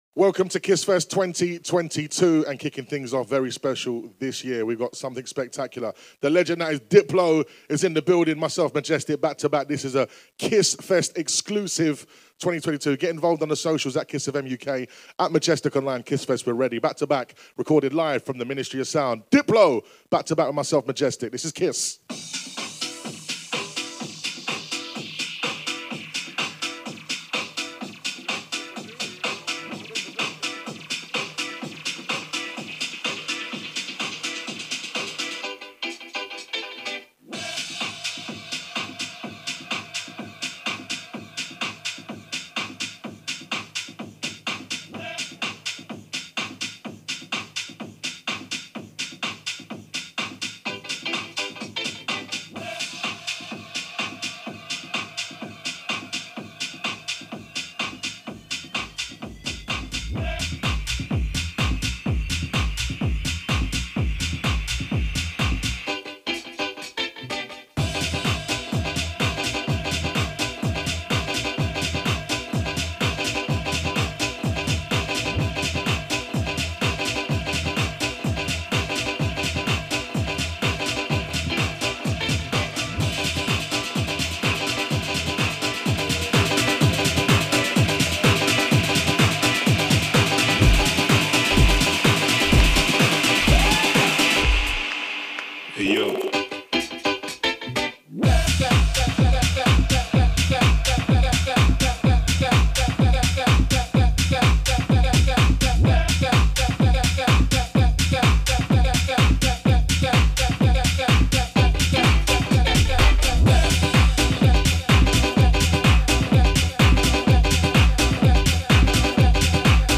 Also find other EDM Livesets, DJ Mixes